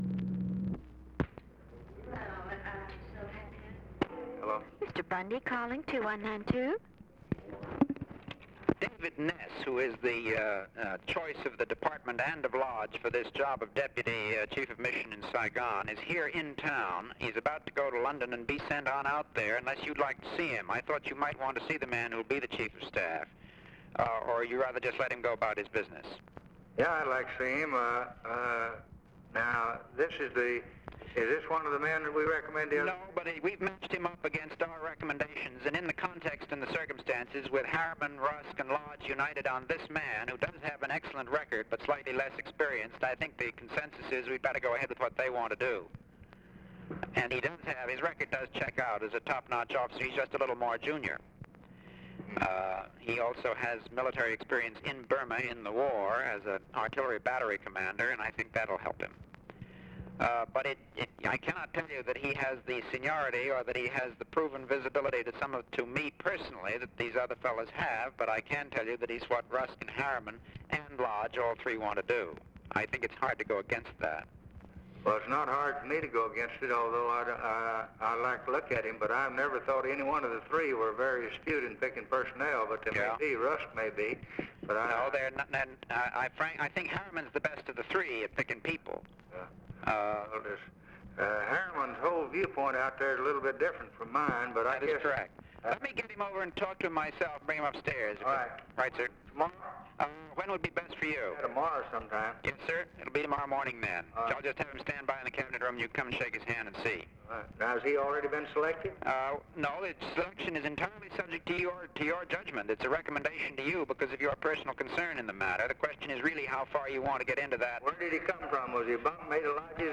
Conversation with MCGEORGE BUNDY, December 9, 1963
Secret White House Tapes